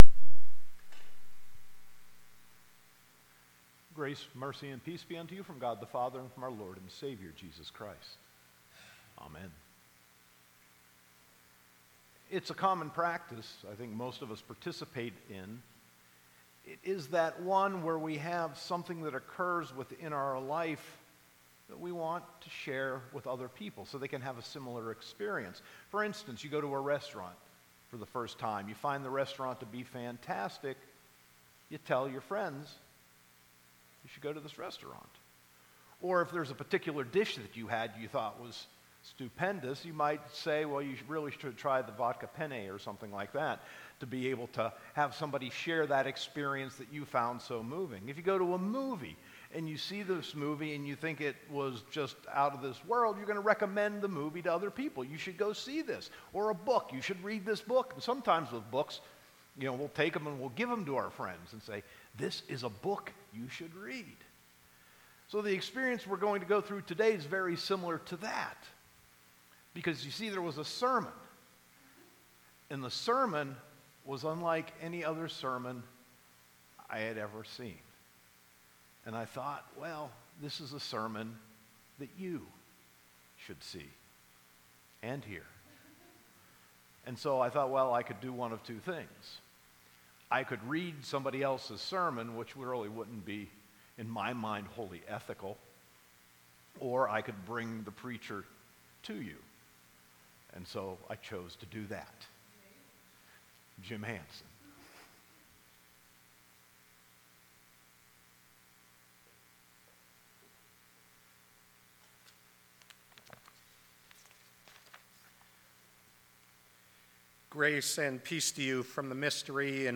Sermon 10.7.2018